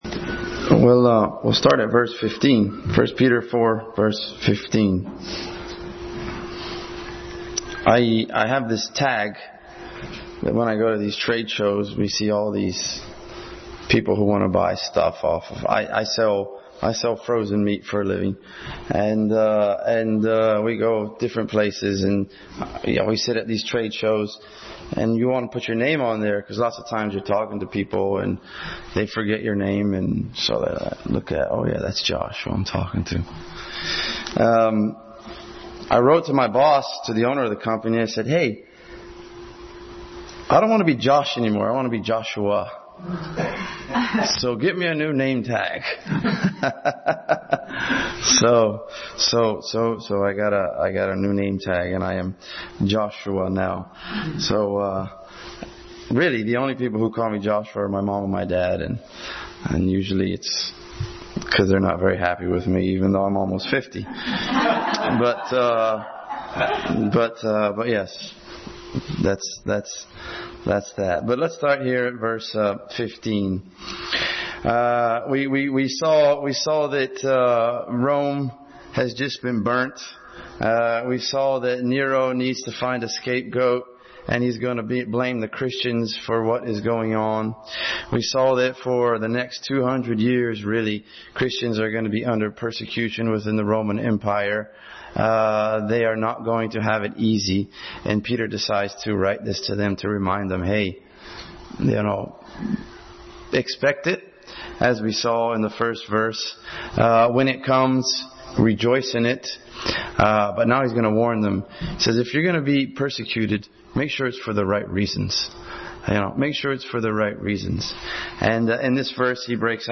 Passage: 1 Peter 4:12-19, Romans 3:23, 6:23, 5:8, 8:1, 10:9 Service Type: Family Bible Hour